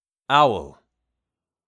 owl.mp3